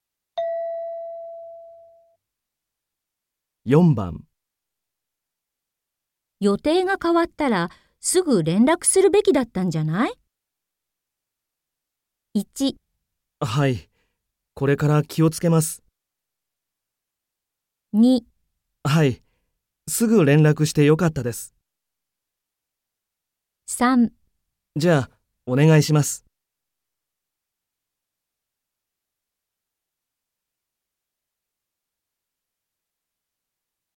問題4 ［聴解］